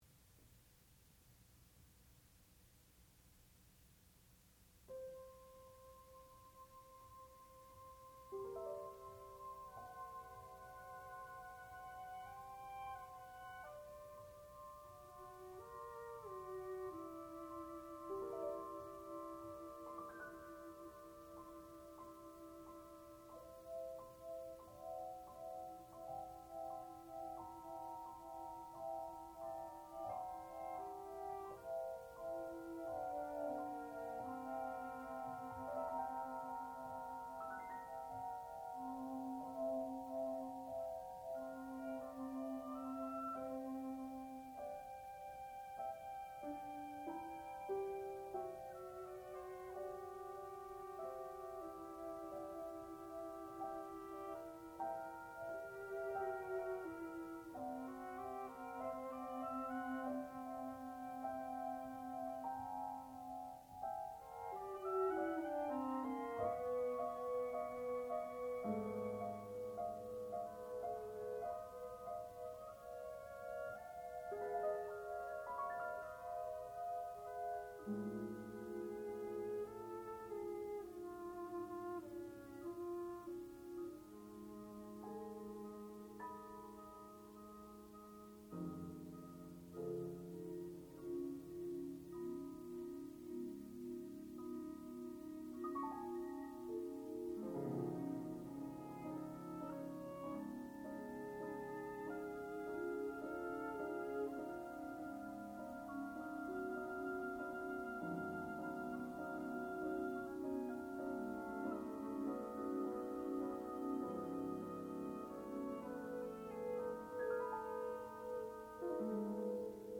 sound recording-musical
classical music
piano
violoncello
percussion